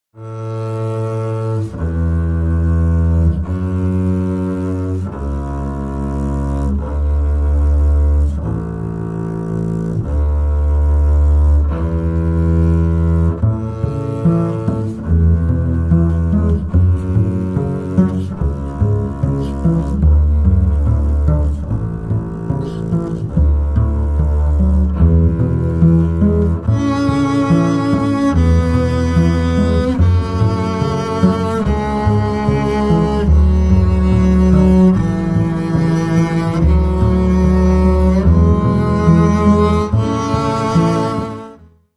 Контрабас
к-бас.mp3